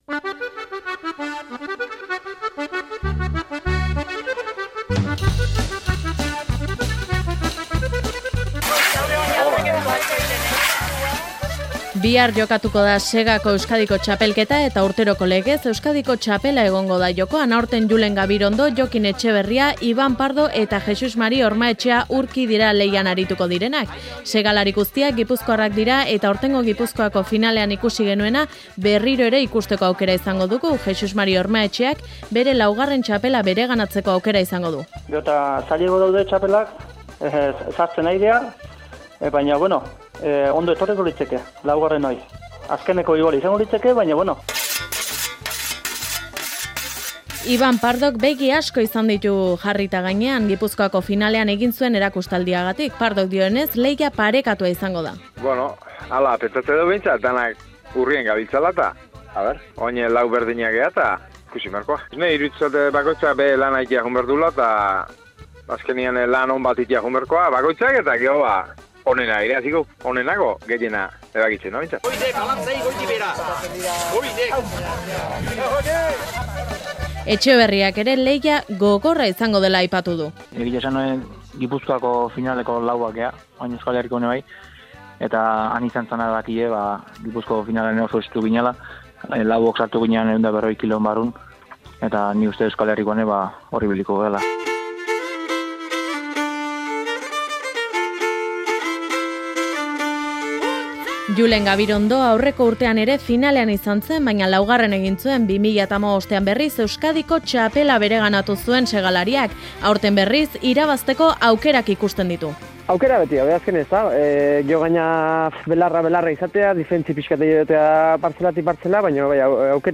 Audioa: Finalean izango diren lau protagonisten hitzak biltzen dituen erreportajea buztuaren 6an izango den lehiaz.